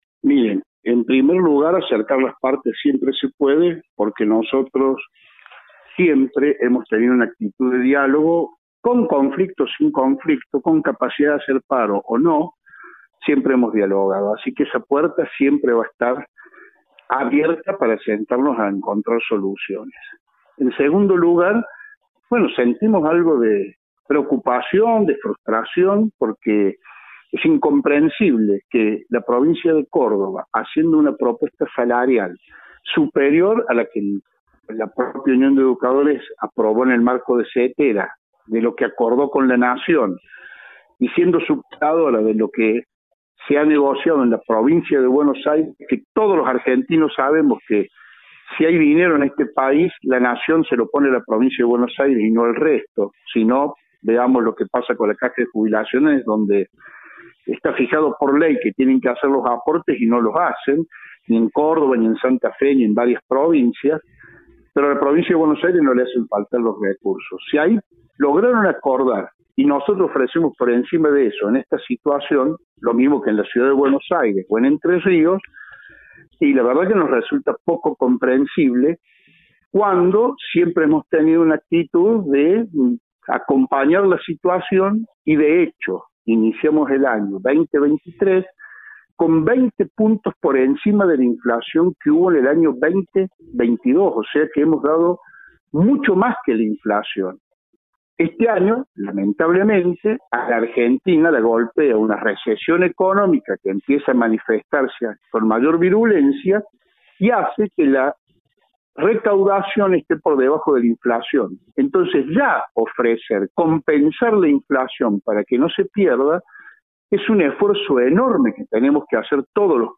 Audio: Walter Grahovac (Ministro de Educación de Córdoba).